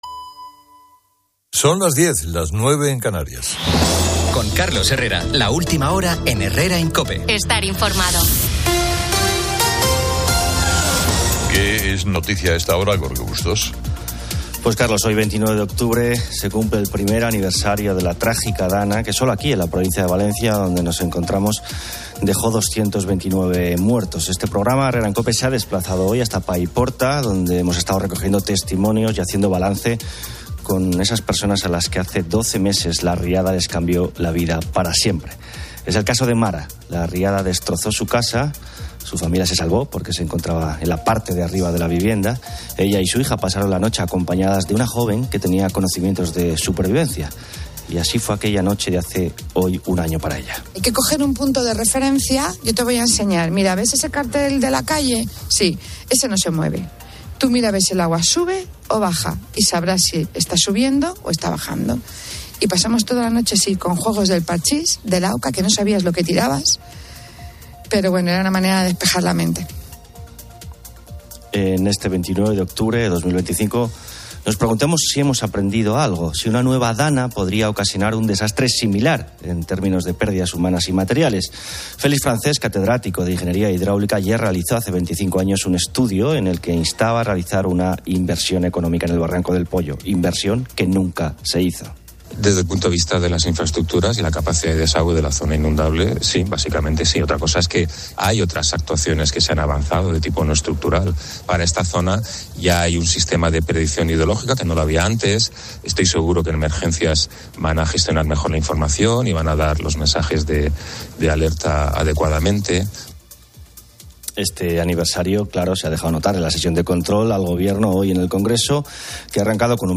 Se cumple el primer aniversario de la DANA que causó 229 muertes en Valencia, con COPE presente en Paiporta para recoger testimonios.
En los estudios de COPE se comenta el uso de redes sociales de políticos y el humor en el deporte, con anécdotas y debates sobre la vida cotidiana.